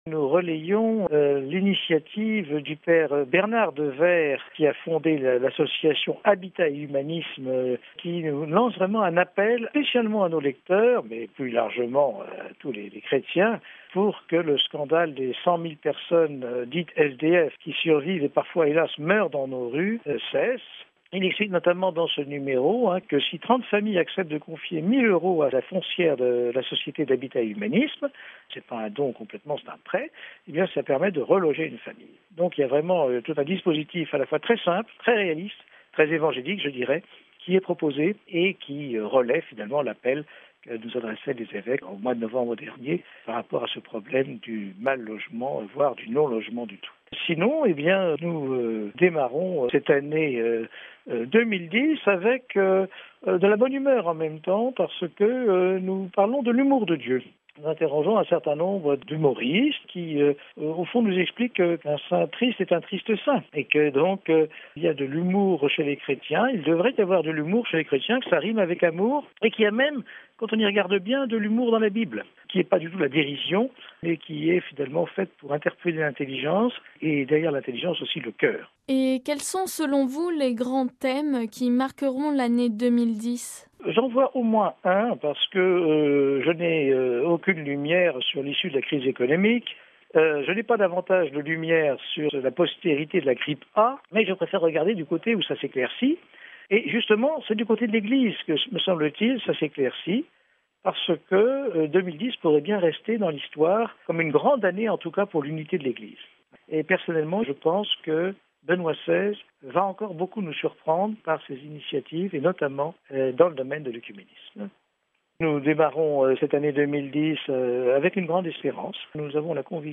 Nous vous proposons d’écouter une série d’entretiens de fin d’année avec quelques responsables de la presse écrite francophone.